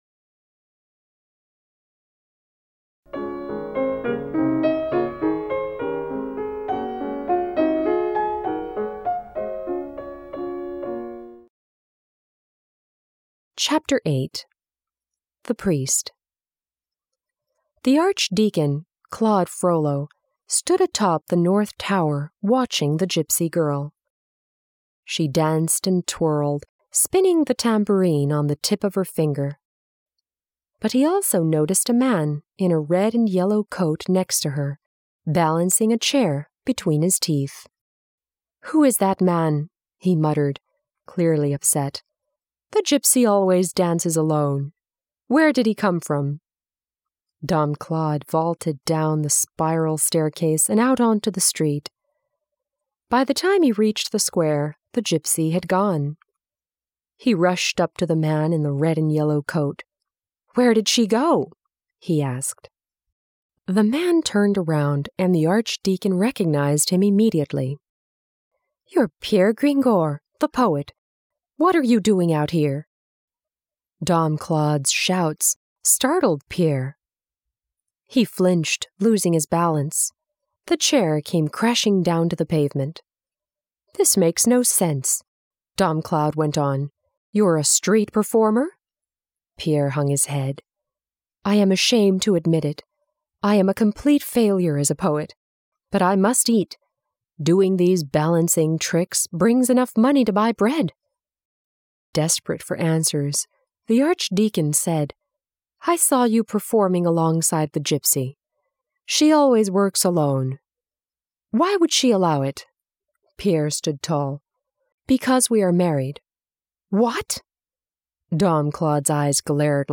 美国教育专家精心编写，儿童文学家黄蓓佳作序推荐，硬壳精装，环保护眼印刷，随书附赠英文有声书